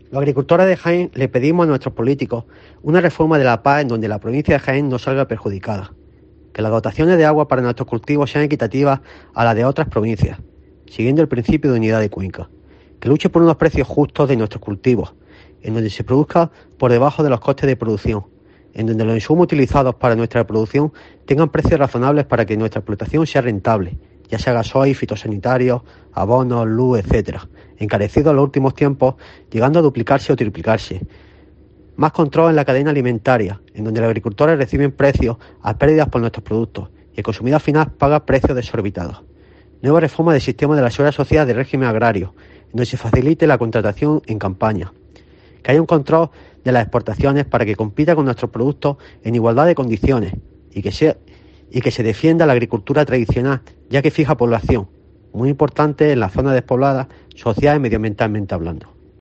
Un olivarero de Jaén pide agua y una buena aplicación de la nueva PAC